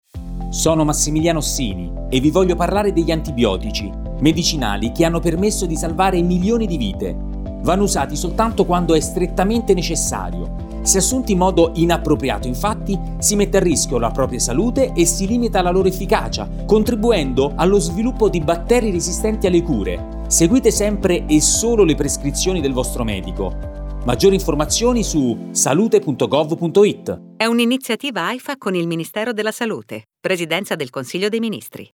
Lo spot radio
antibiotici_radio30_pcm.mp3